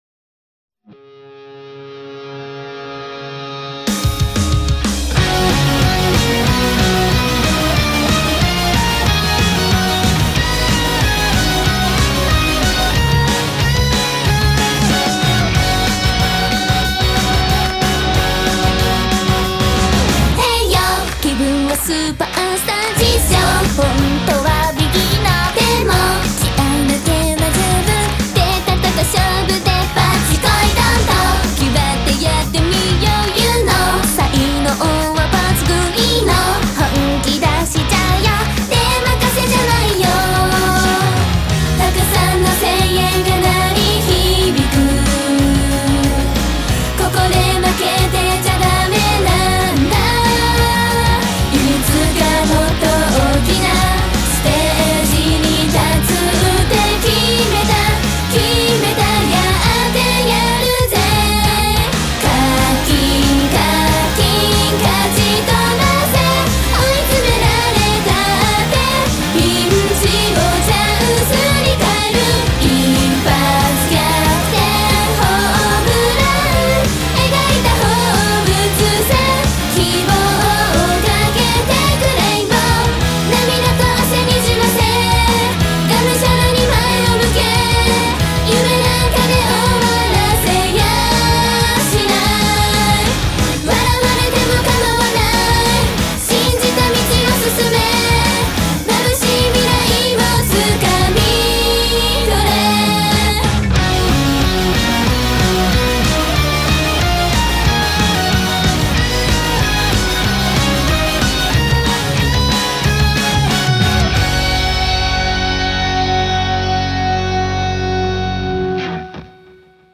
BPM185
Audio QualityCut From Video